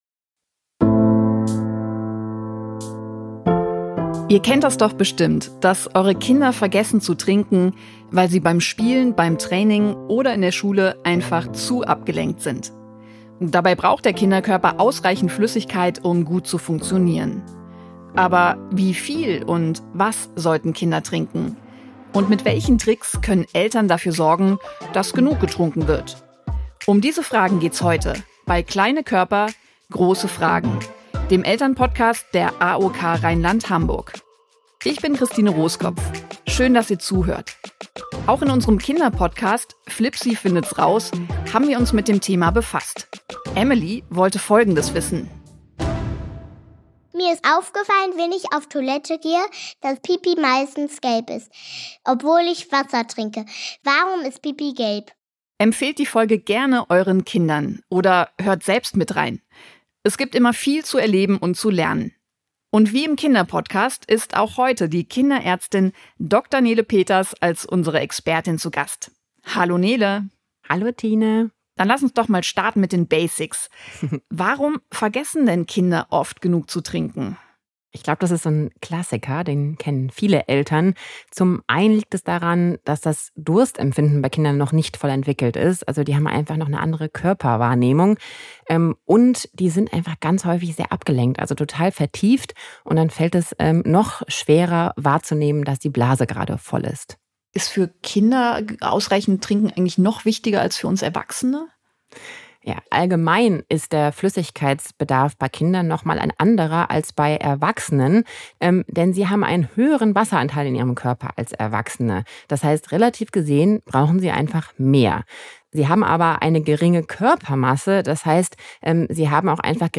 spricht in dieser Folge mit der Kinderärztin